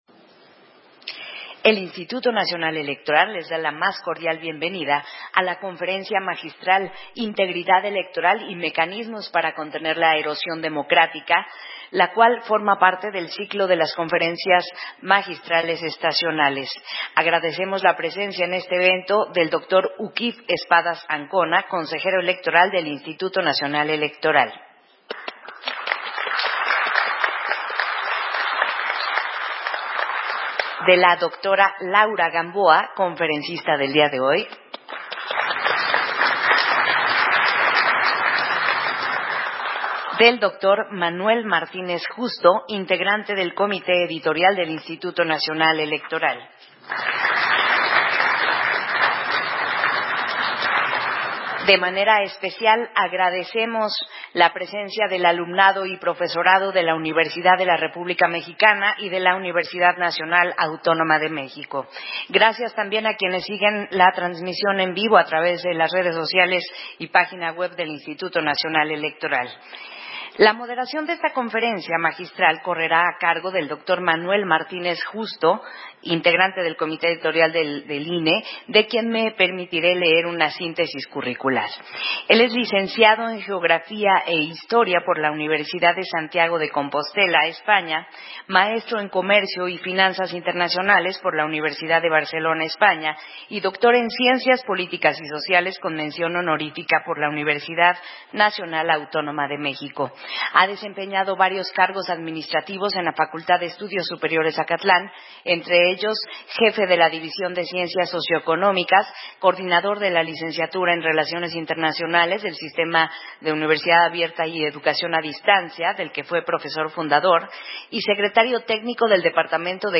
Conferencia Magistral, Integridad Electoral y mecanismos para contender la erosión democrática